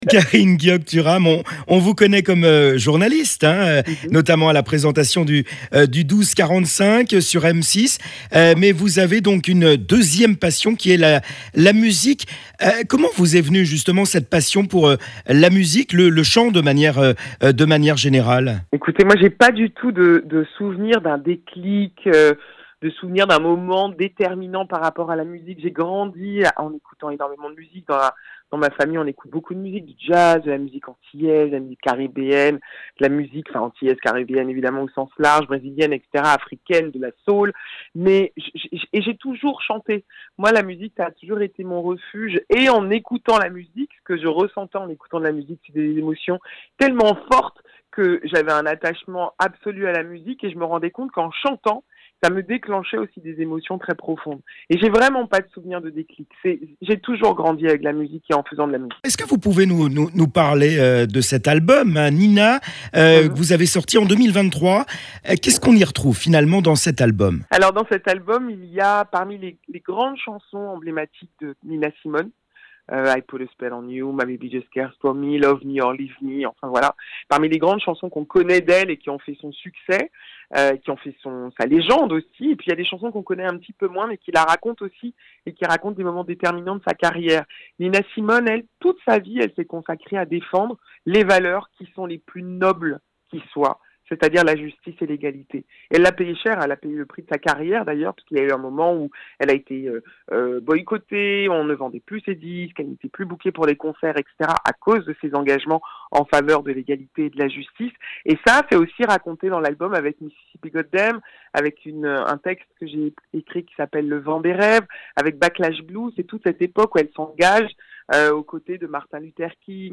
interview-kareen-guiock-thuram-47631.mp3